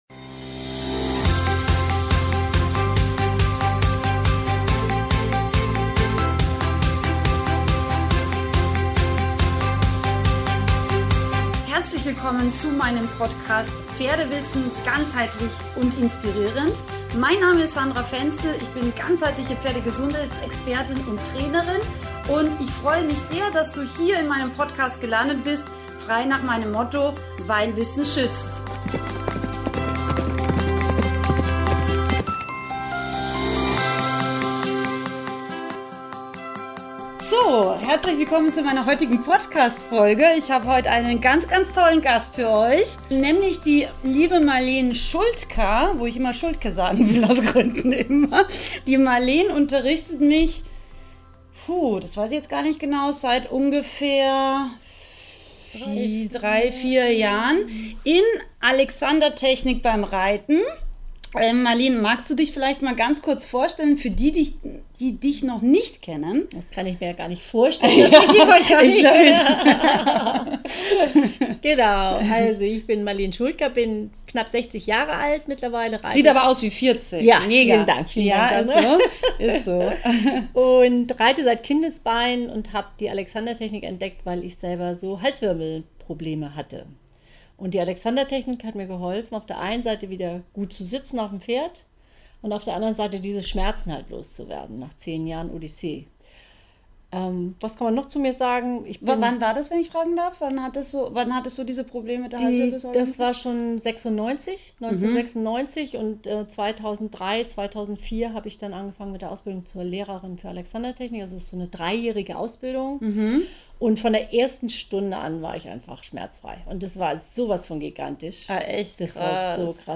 In diesem Pferdepodcast-Interview lernst Du, warum die Alexandertechnik für Reiter so wertvoll ist, und wie Du Deinen Reitersitz verbesserst.